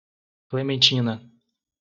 Pronounced as (IPA) /kle.mẽˈt͡ʃĩ.nɐ/